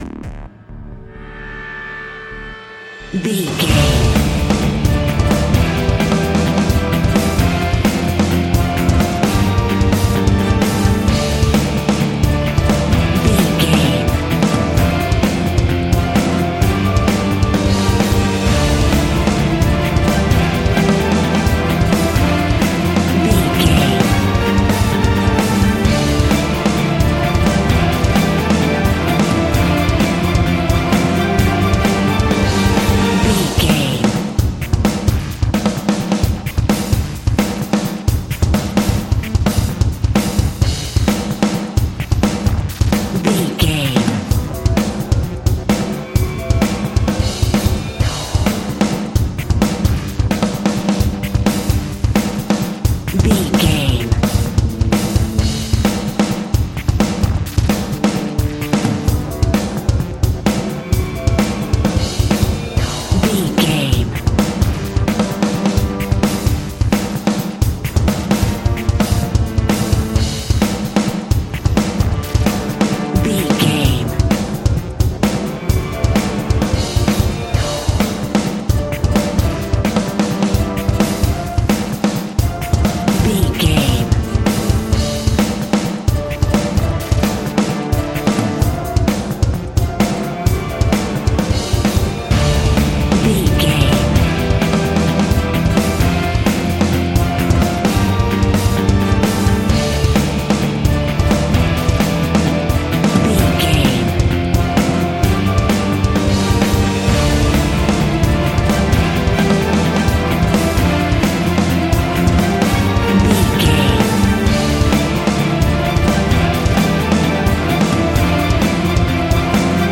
In-crescendo
Thriller
Aeolian/Minor
D
Fast
scary
tension
ominous
dark
suspense
eerie
drums
strings
synths
electronics
staccato strings
staccato brass
viola
french horn
taiko drums
glitched percussion